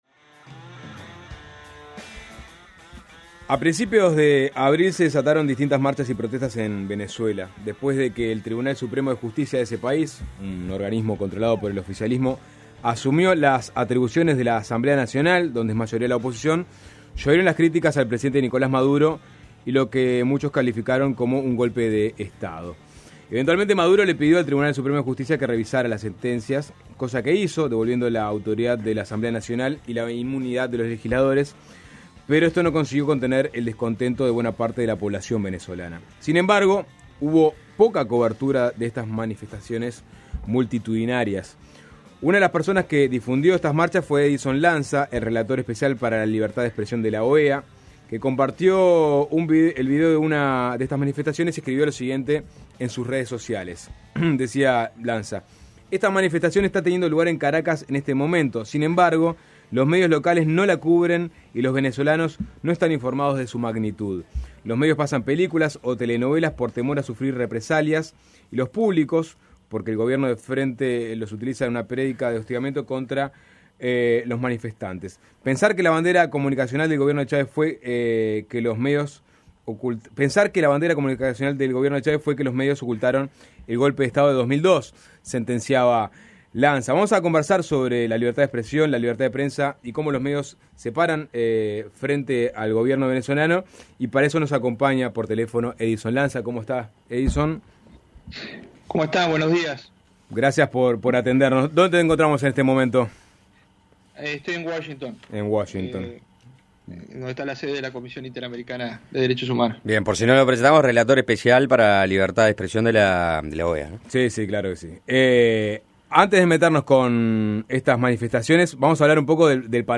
Entrevista en Suena Tremendo